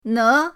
ne2.mp3